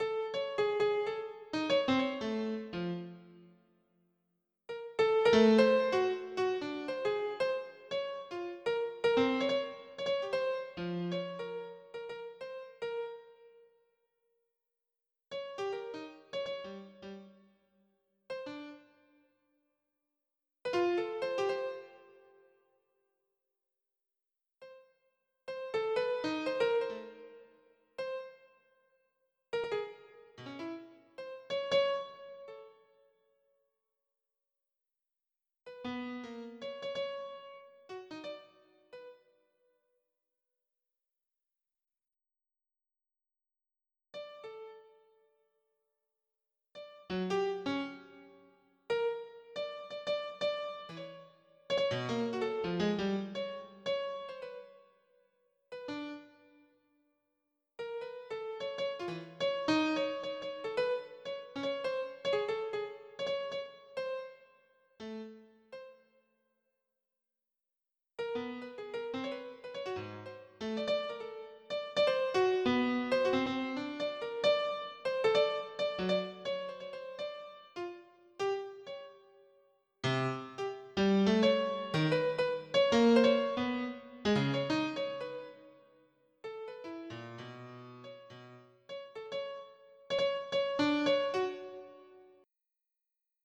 • Качество: 320, Stereo
без слов
пианино
море